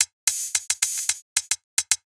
Index of /musicradar/ultimate-hihat-samples/110bpm
UHH_ElectroHatD_110-02.wav